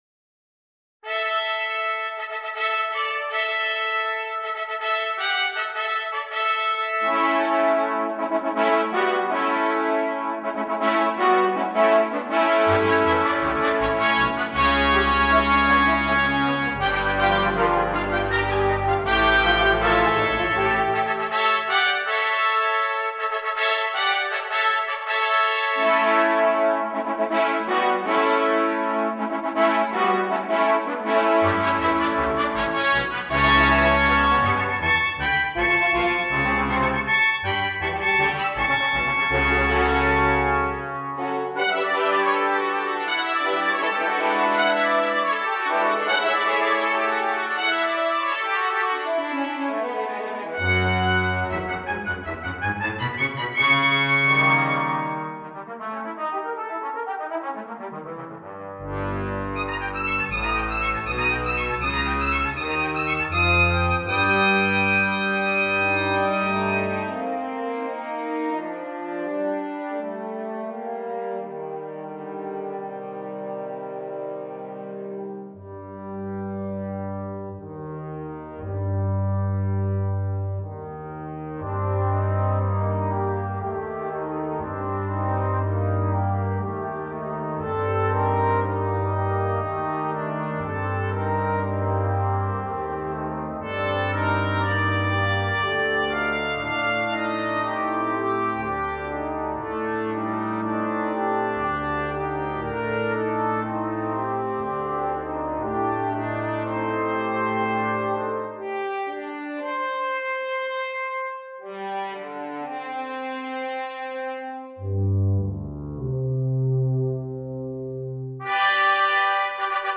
Chamber